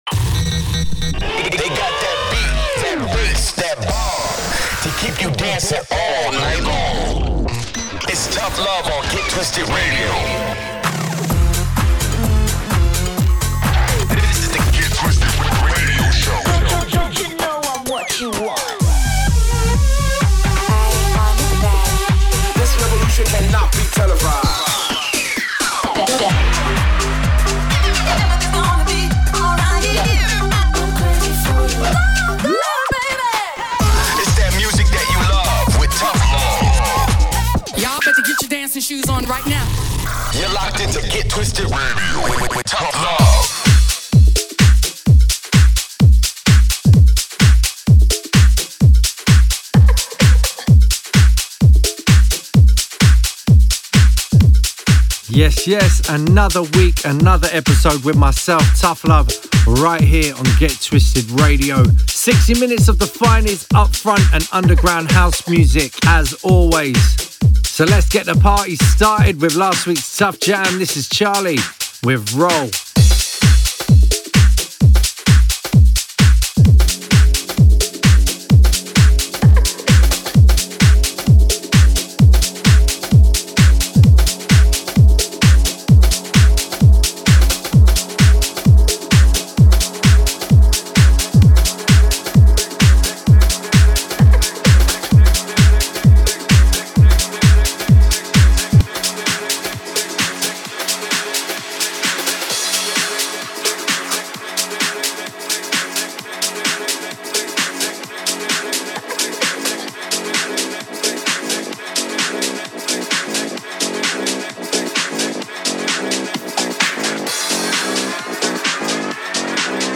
upfront & underground house music